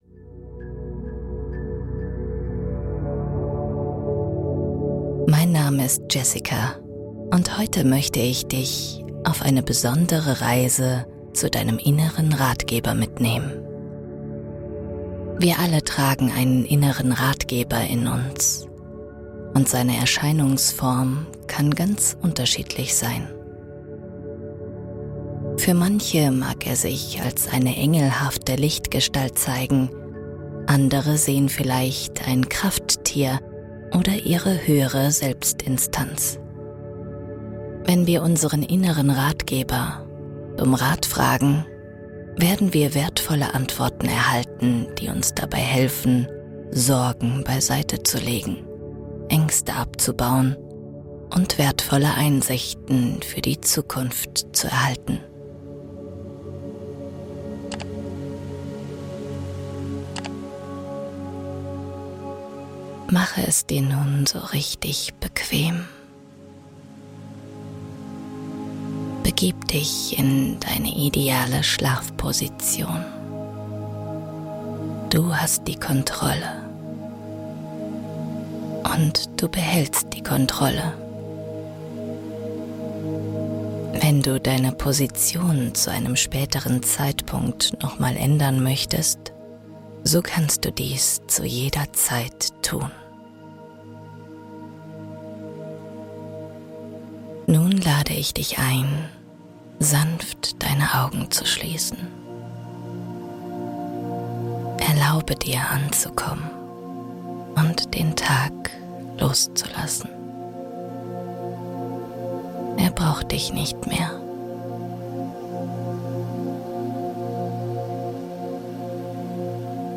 Sorgen-Stopper - Diese Hypnose löscht Ängste in einer Nacht